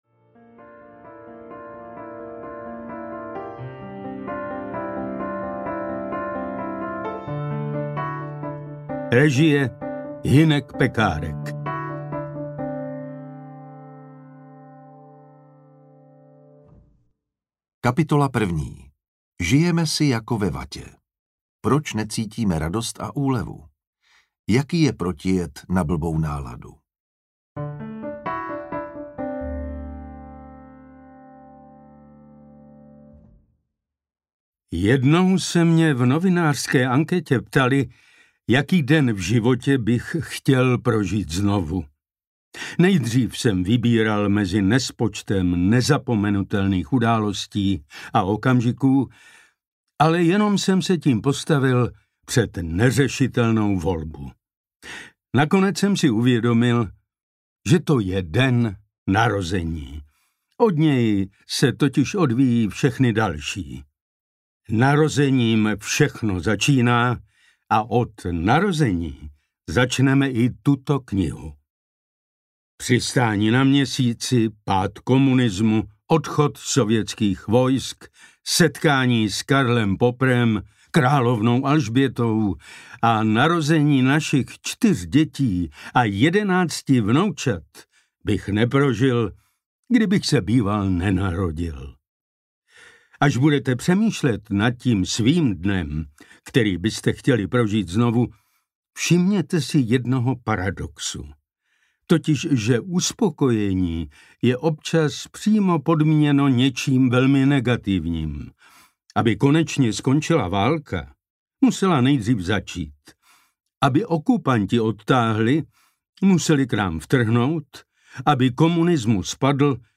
Tak o co jde audiokniha
Ukázka z knihy
• InterpretPetr Štěpánek, Jan Vondráček